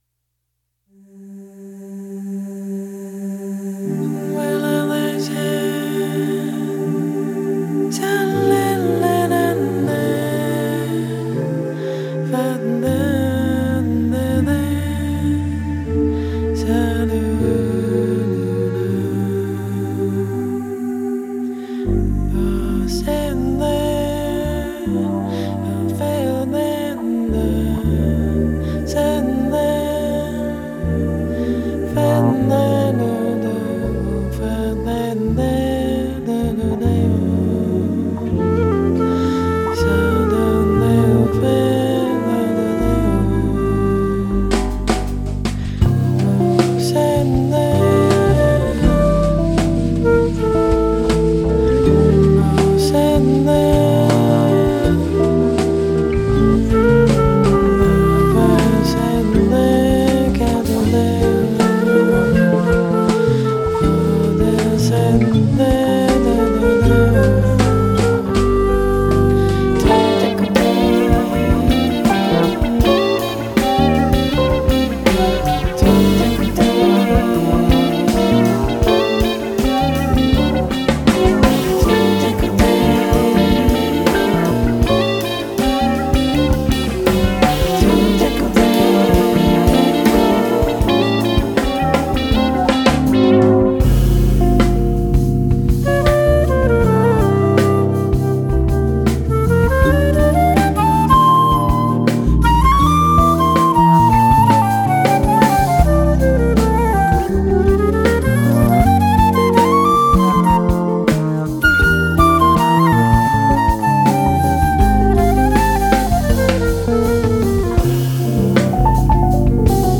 bass guitar, doublebass
flute, pipes, voice
keyboards
drums